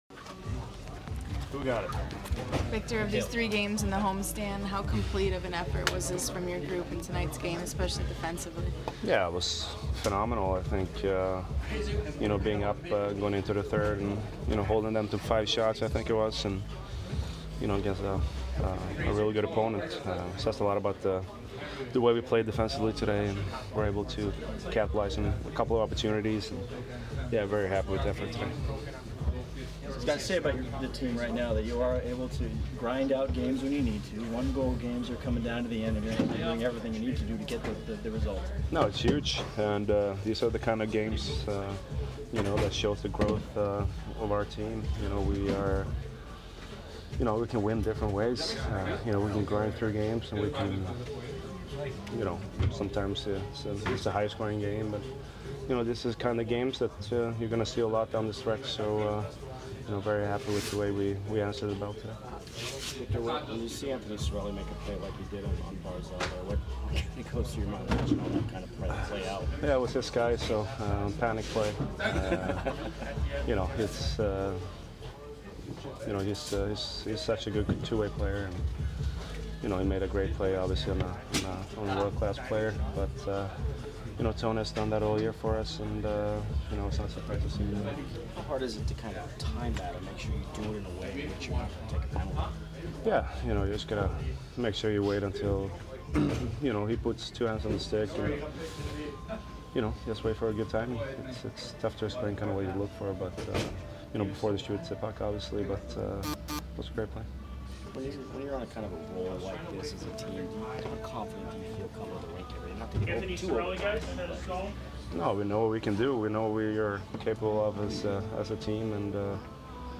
Victor Hedman post-game 2/8